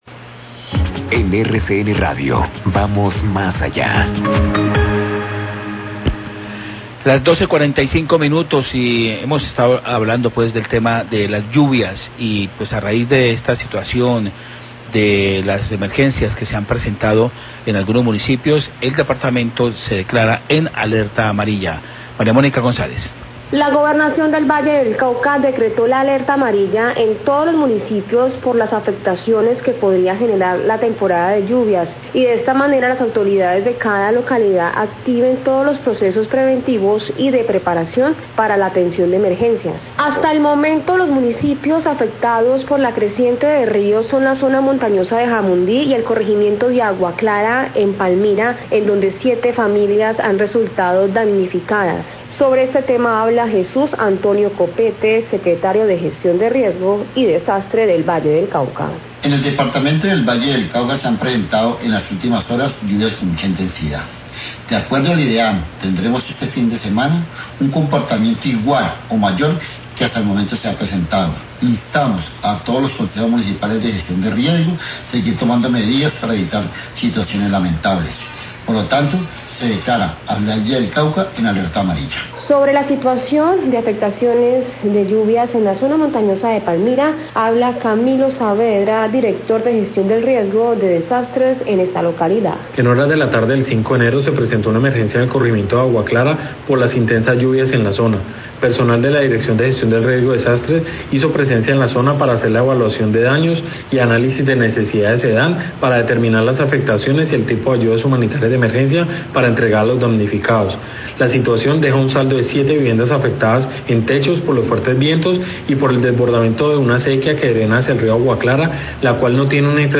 Radio
El departamento del Valle del Cauca fue declarado en alerta amarilla por las fuertes lluvias presentes y que ha generado emergencias en esta región. Declaraciones del Secretario de Gestión del Riesgo del Valle, Jesús Copete, y el de Palmira.